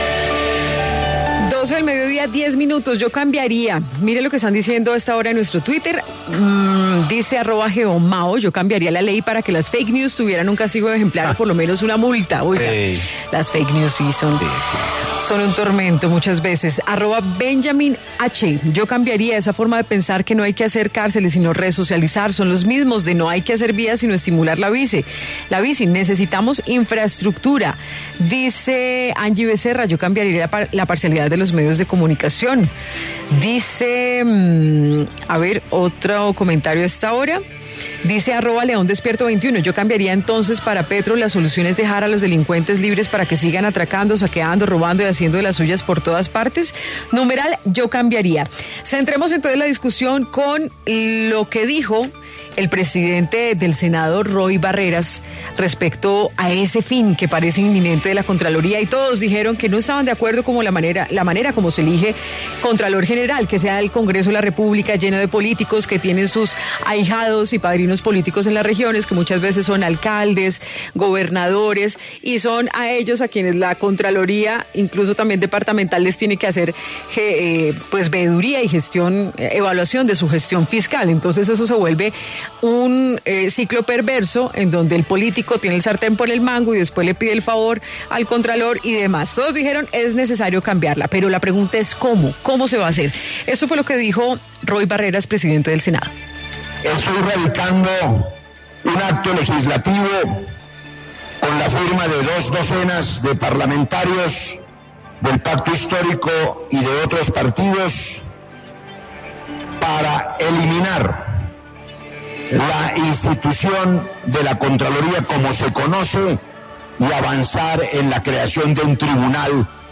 Edgardo Maya Villazón, excontralor General de la Nación, conversó en Sigue La W sobre la propuesta de crear un Tribunal de Cuentas y eliminar la Contraloría.